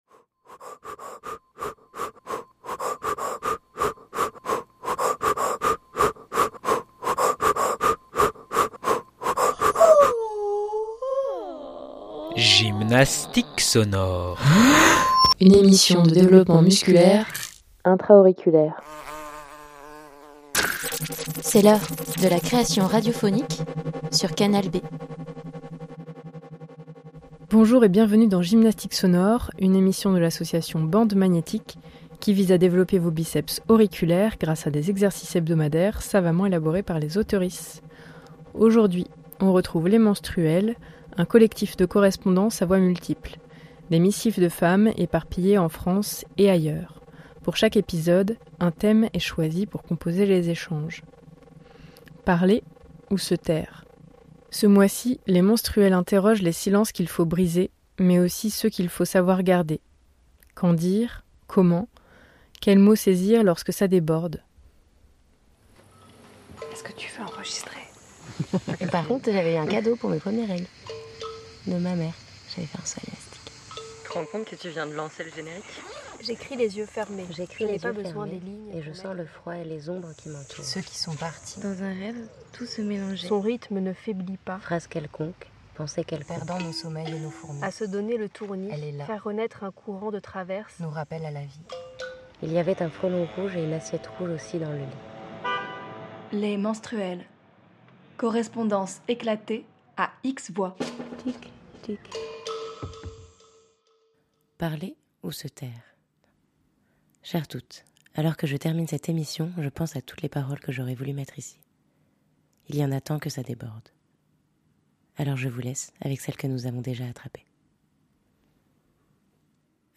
Le rendez-vous de la création radiophonique.
Les Menstruelles #77 - Naufrage de la peur 13/12/2025 60 mn Les Menstruelles , c'est un collectif de correspondances à voix multiples. Des missives de femmes éparpillées en France et ailleurs.